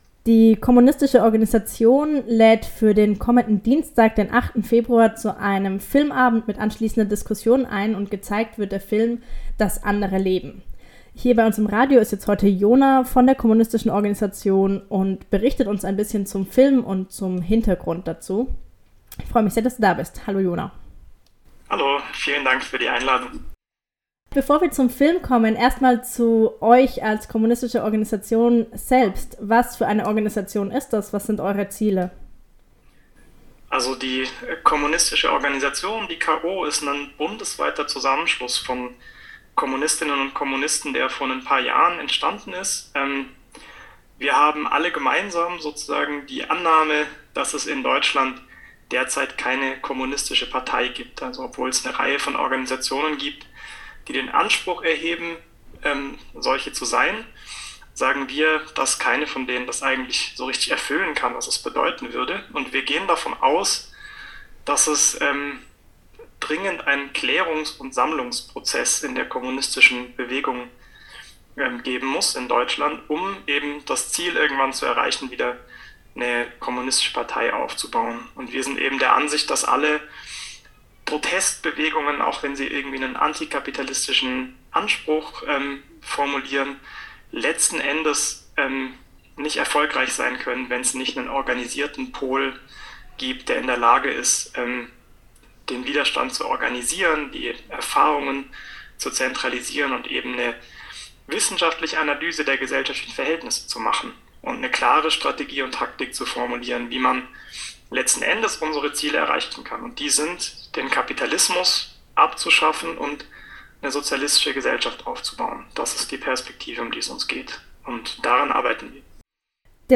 KO Interview kurz